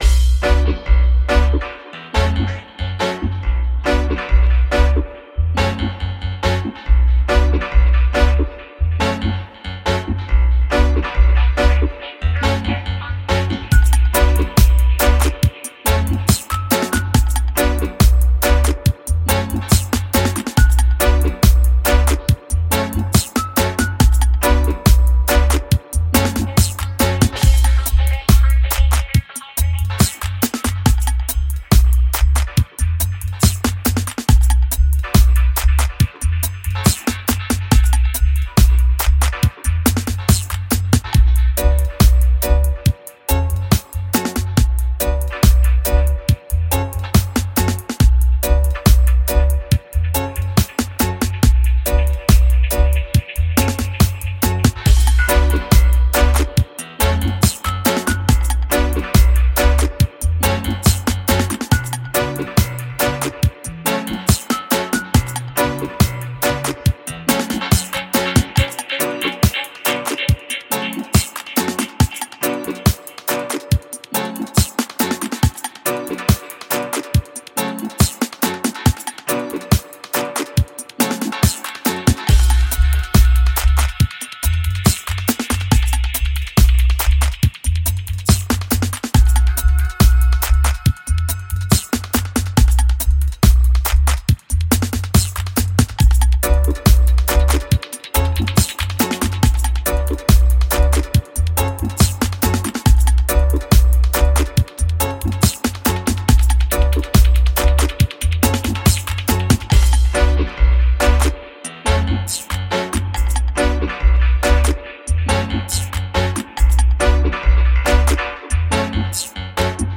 深いベース、流れるようなグルーヴ、温かみのある有機的な楽器構成を備えたハーフステップのモダンルーツダブパックです。
注：付属のステムはデモで聴こえる通りにミックス・パンニングされています。
ベース＆ドラム: ヘビーで流れるようなベースとハーフステップドラムグルーヴ
キー＆オルガン: エレキキー、ピアノ、オルガンバブル
ホーンズ: トランペット、トロンボーン、サックス（個別およびコンボ）
ギター: レゲエスカンク
パーカッション: カウベル、ギロ、タンバリン
Genre:Dub
Key:Bm
Tempo Range: 140 bpm